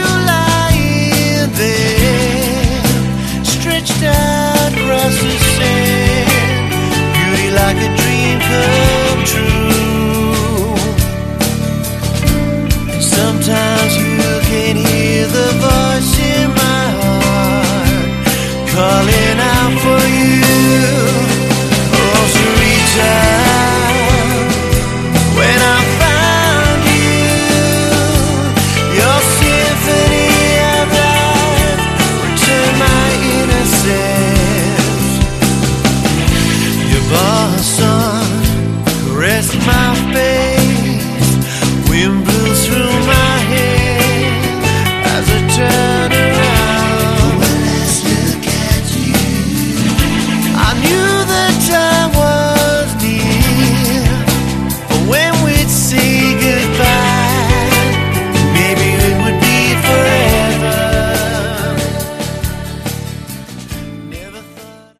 Category: Hard Rock
vocals
guitars, backing vocals
drums